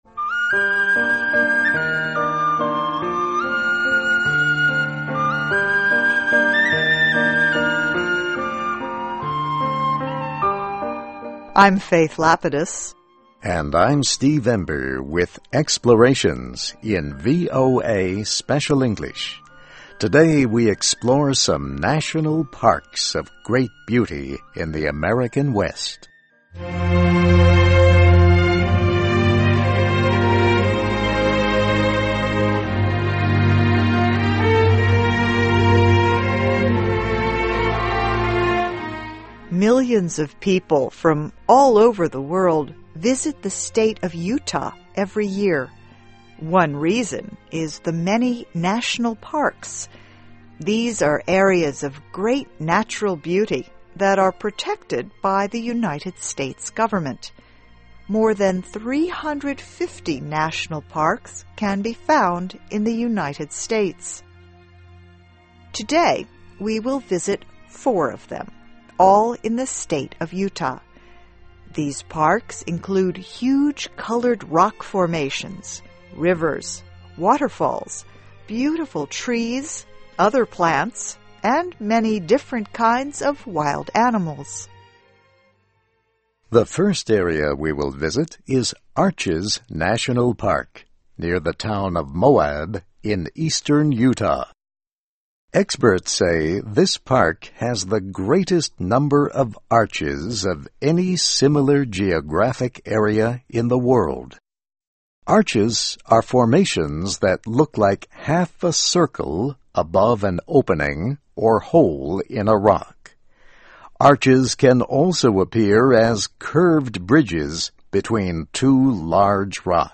Places: Four National Parks in Utah: Arches National Park, Canyonlands National Park, Bryce Canyon National Park and Zion National Park (VOA Special English 2009-04-14)
Listen and Read Along - Text with Audio - For ESL Students - For Learning English